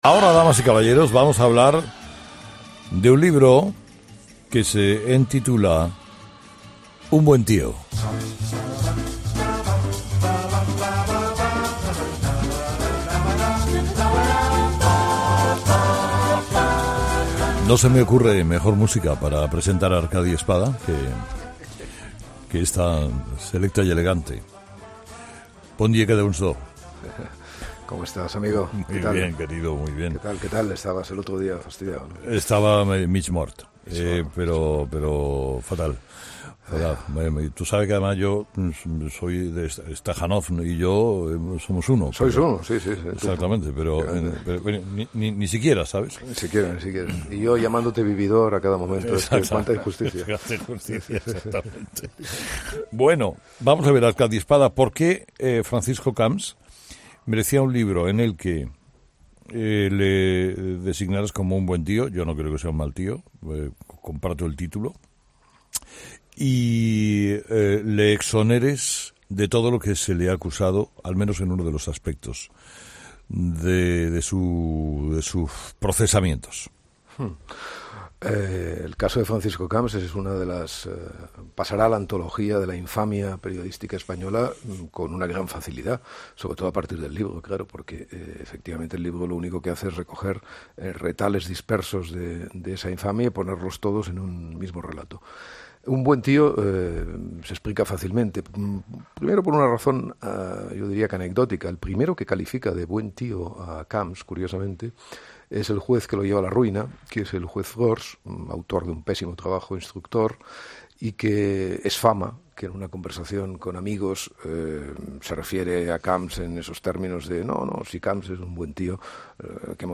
Entrevista a Arcadi Espada por la presentación de su libro 'Un buen tío'